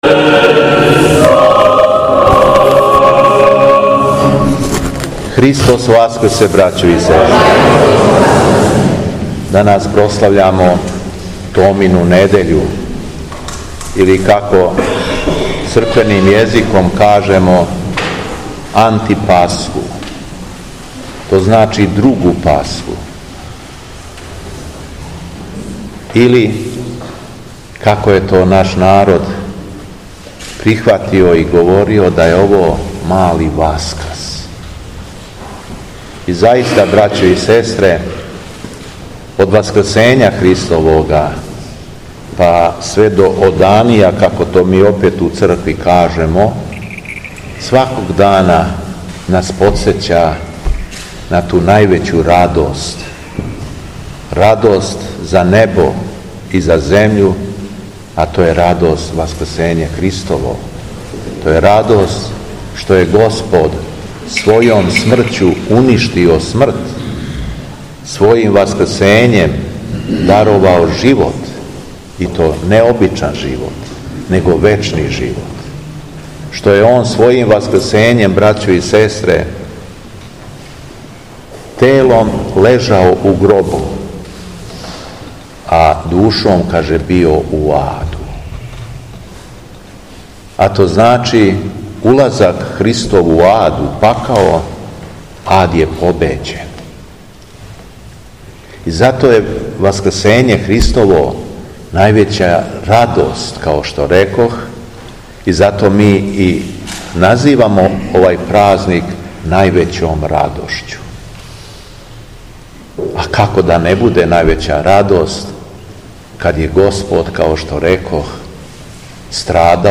Беседа Његовог Високопреосвештенства Митрополита шумадијског г. Јована
Након прочитаног јеванђеља Митрополит се обратио сакупљеном народу: